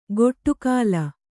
♪ goṭṭu kāla